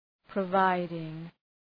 Προφορά
{prə’vaıdıŋ}